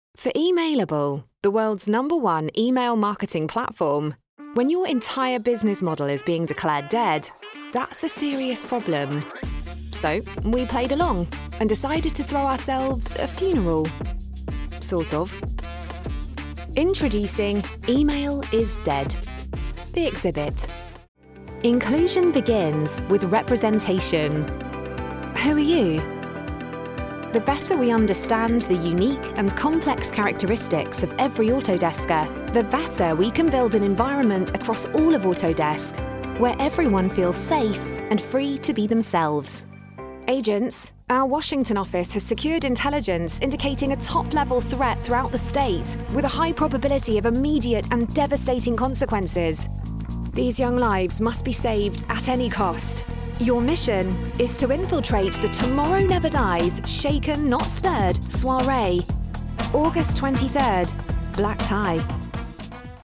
English (British)
Corporate Videos
Bright, warm, fresh, natural and professional are just some of the ways my voice has been described.